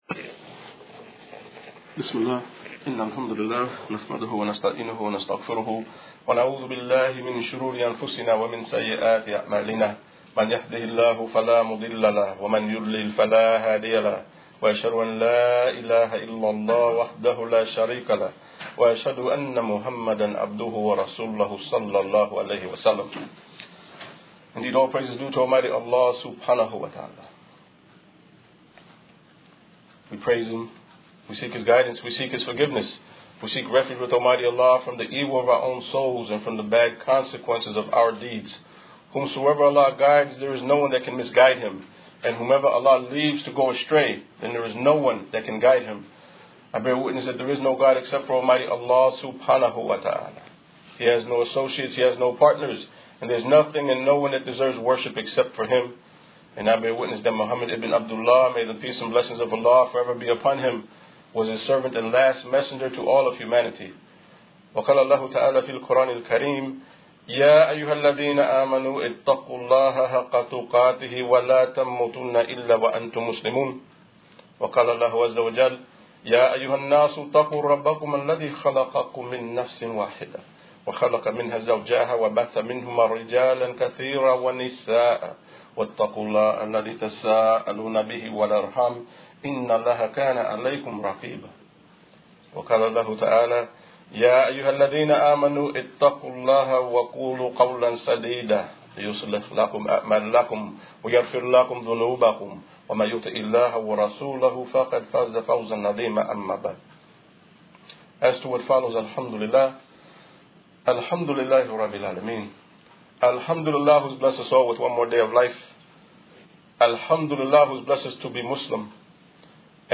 To hear this audio khutbah, please click here: Disaster Preparation